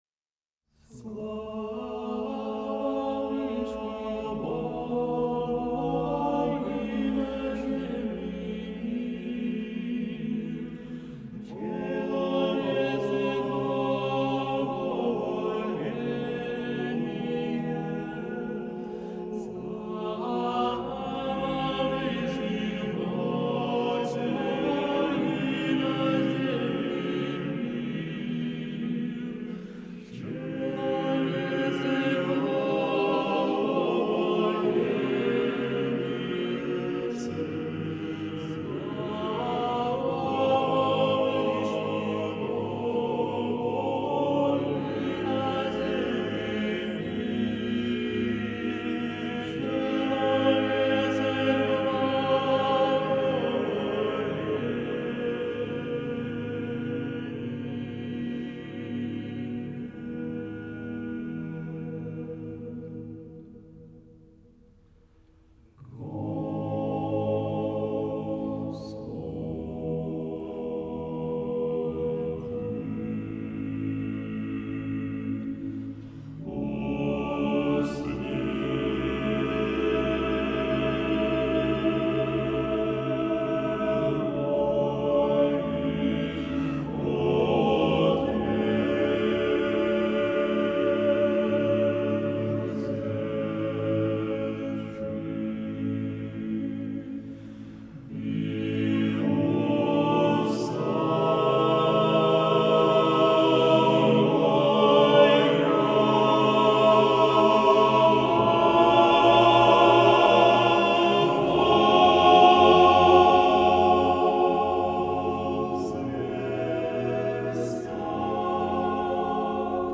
Платный мастеринг вокального ансамбля
Мужской хор Санкт-Петербургского Подворья монастыря Оптина Пустынь под управлени.mp3 Мужской хор Санкт-Петербургского Подворья монастыря Оптина Пустынь под управлени.mp3 5,9 MB · Просмотры: 1.188